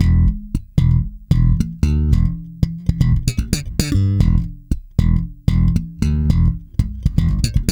-JP THUMB E.wav